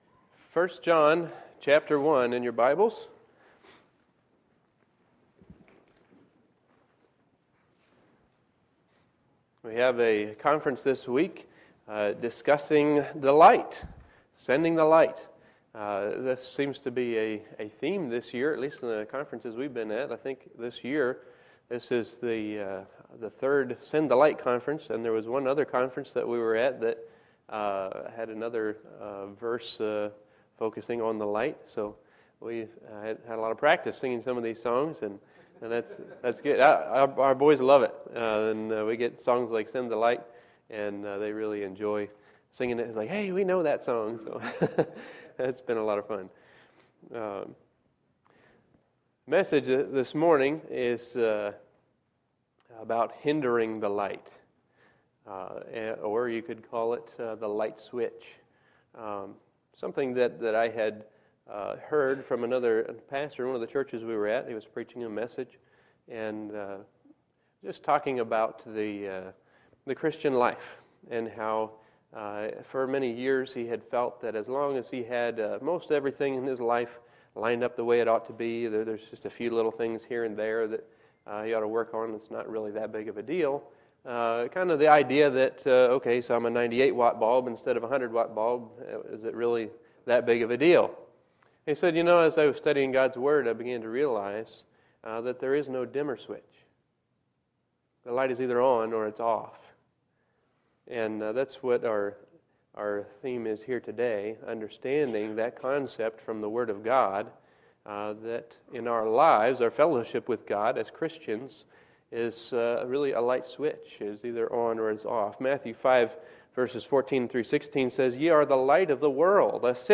Sunday, September 23, 2012 – Sunday AM Session